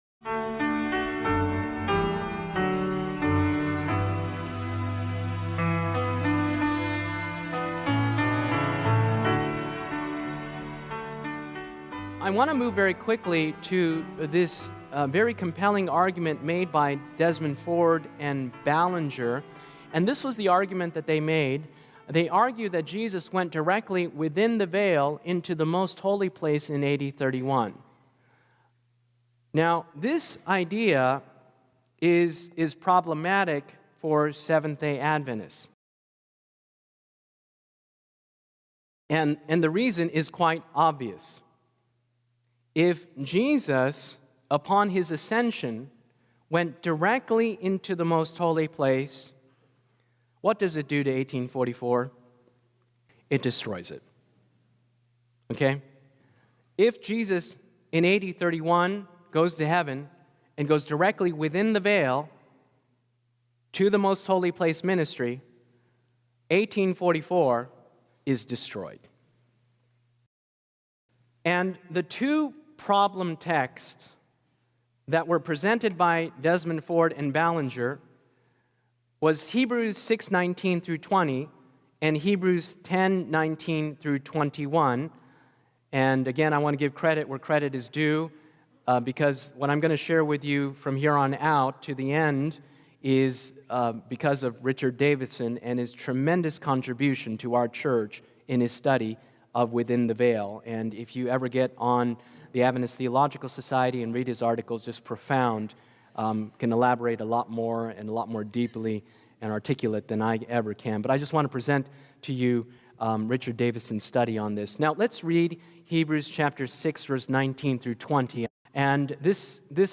Explore how deep biblical and linguistic analysis affirms the Adventist understanding of Christ’s heavenly ministry, showing that His entrance “within the veil” marked an inauguration—not the Day of Atonement. This sermon unpacks Hebrews through chiastic structure, Greek word studies, and sanctuary typology to reinforce the 1844 doctrine and Adventist identity.